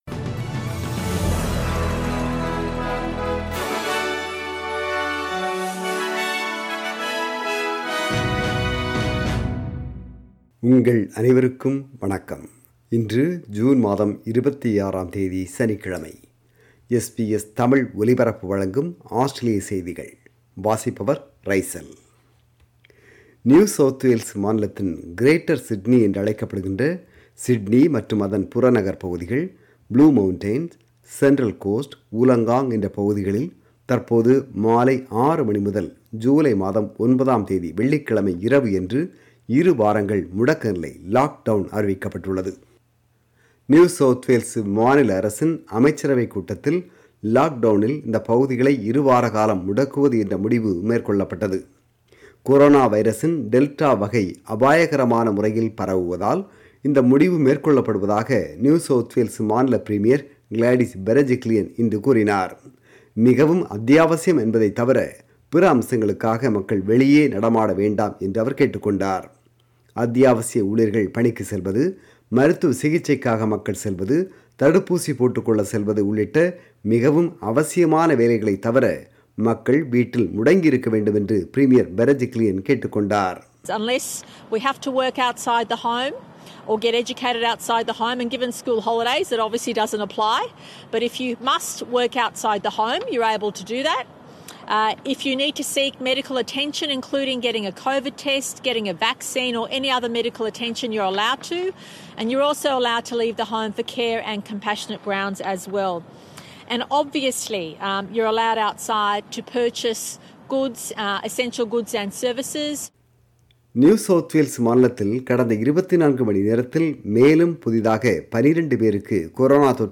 ஆஸ்திரேலிய செய்திகள்